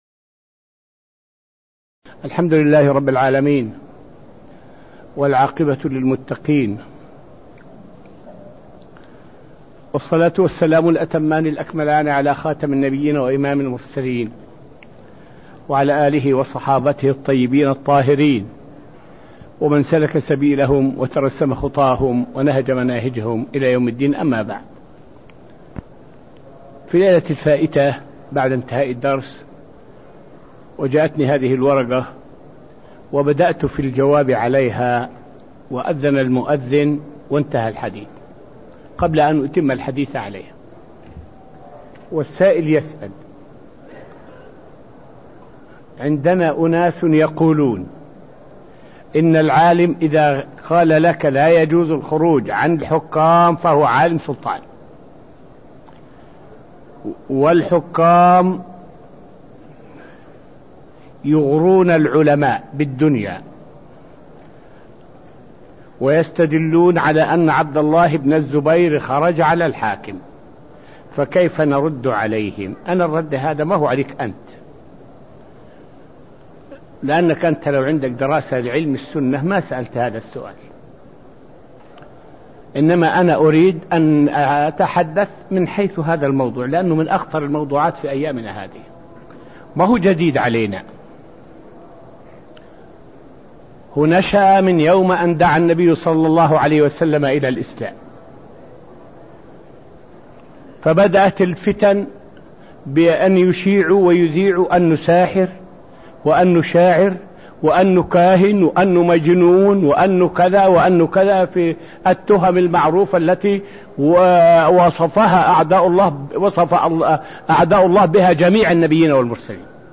تاريخ النشر ١٢ صفر ١٤٢٦ المكان: المسجد النبوي الشيخ